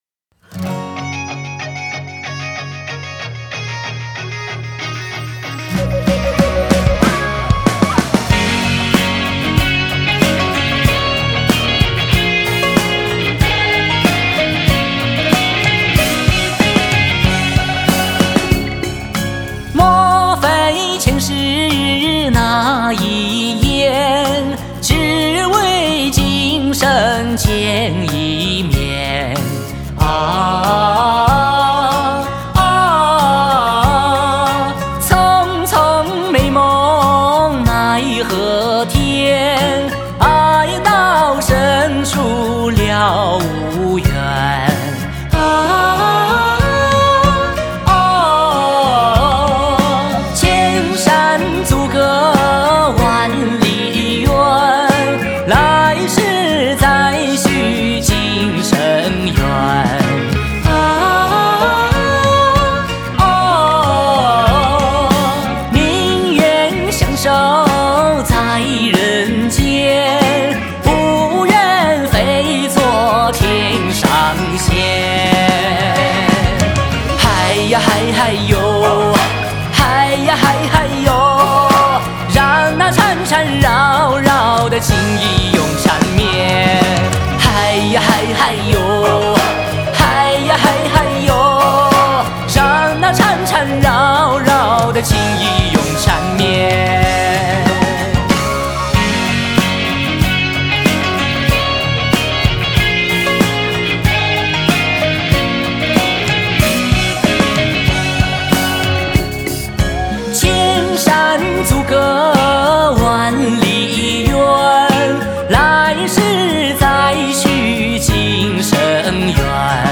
Ps：在线试听为压缩音质节选，体验无损音质请下载完整版 莫非前世那一眼 只为今生见一面 啊……啊……